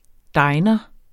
Udtale [ ˈdɑjnʌ ]